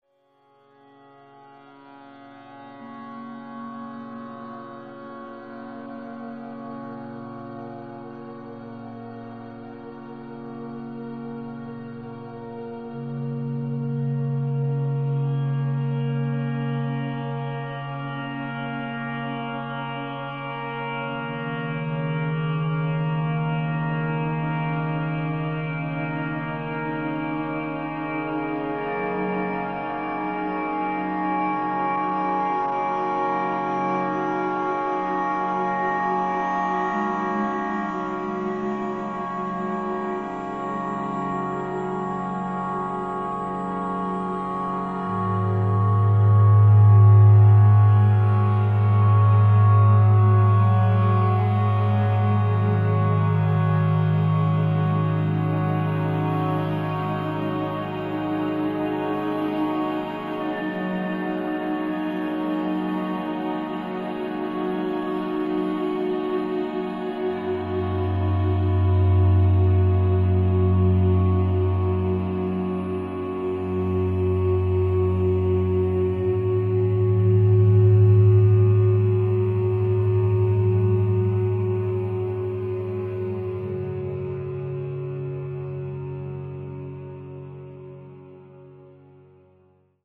Chor, Klangschalen und einen Synthesizersound